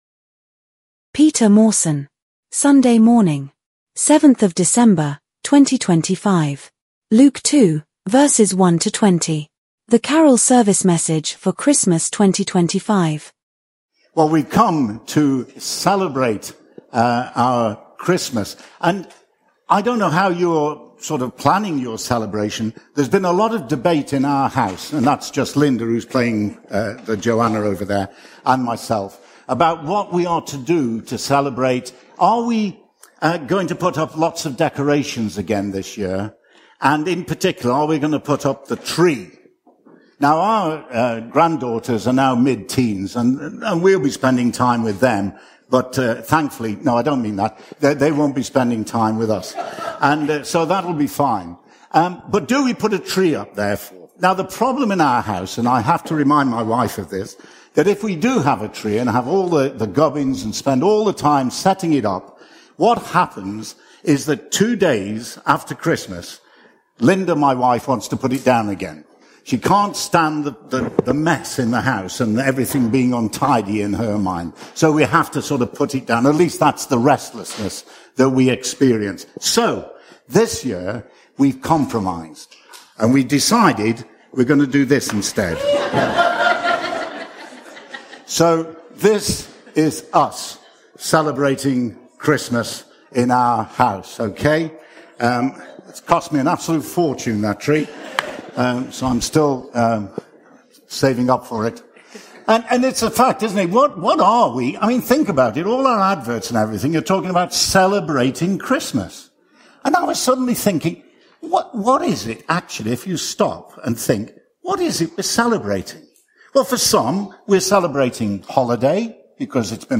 Carol Service Message 2025